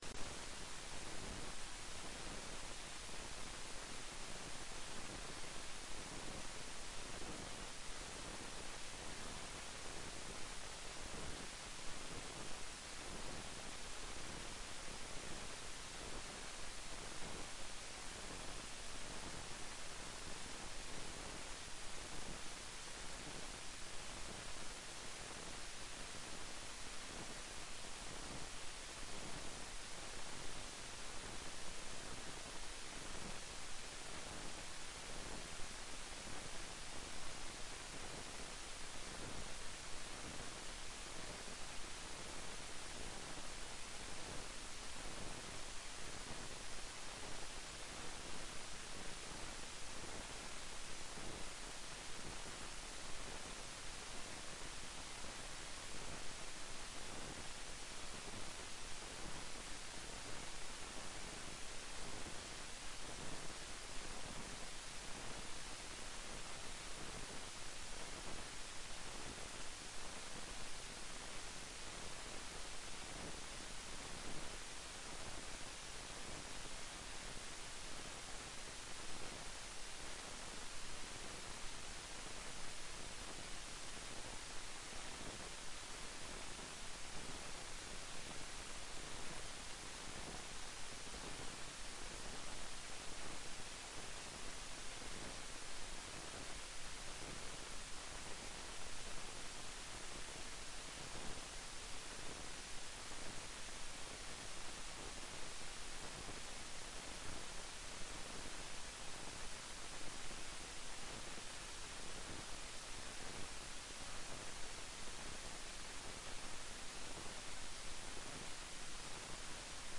Kjemiforelesning 5
Rom: Store Eureka, 2/3 Eureka